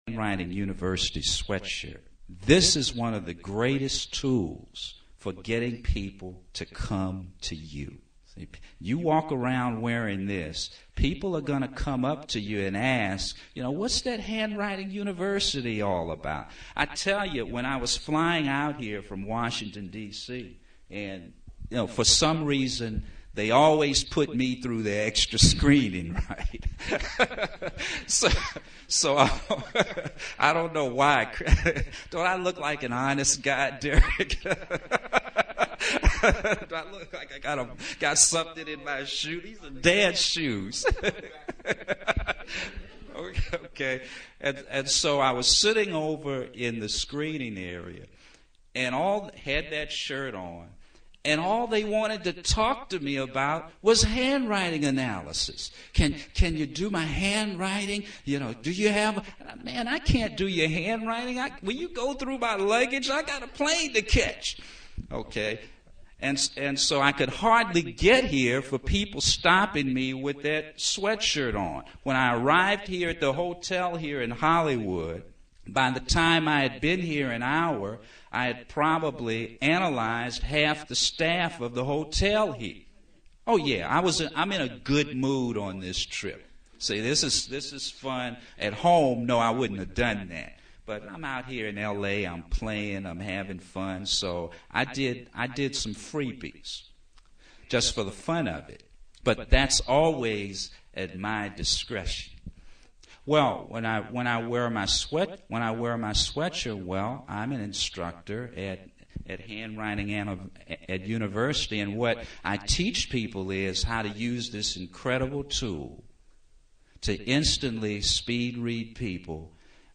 Handwriting Analysis Seminar Earn Money_